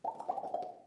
大量的录音根特" 008手指从左到右弹出T3
描述：声音是在比利时根特的大规模人民录音处录制的。
一切都是由4个麦克风记录，并直接混合成立体声进行录音。每个人都用他们的手指在嘴里发出啪啪的声音。从一边平移到另一边。